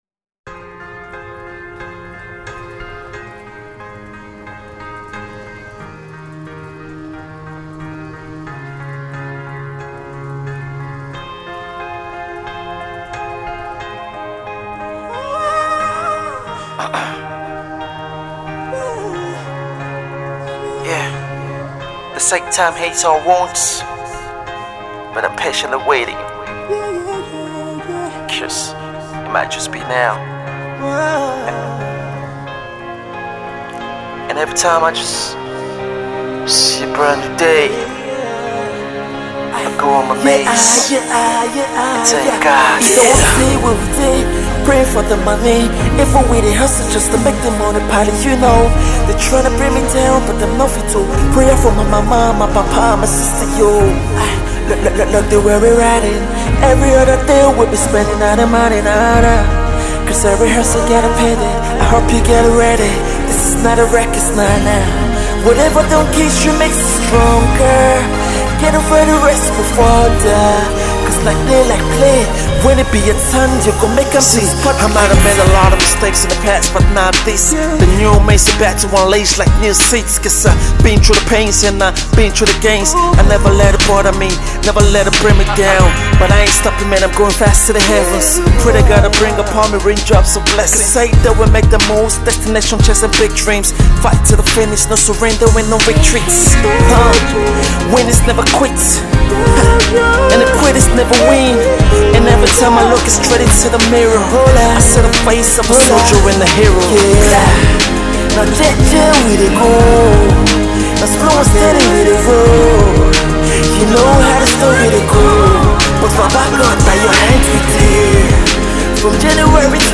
mellow tune
Its not a bad Rap song at all